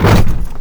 sounds / suspension / compress_heavy_2.wav
compress_heavy_2.wav